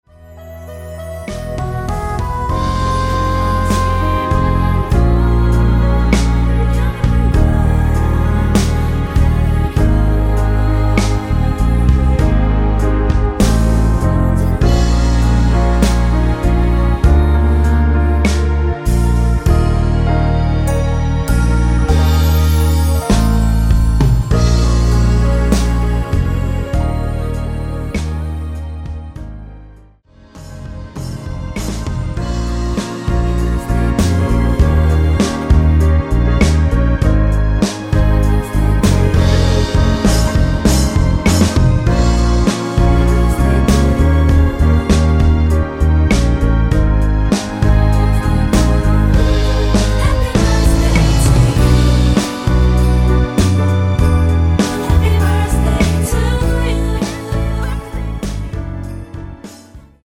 코러스 포함된 MR 입니다.(미리듣기 참조)
Ab
앞부분30초, 뒷부분30초씩 편집해서 올려 드리고 있습니다.
중간에 음이 끈어지고 다시 나오는 이유는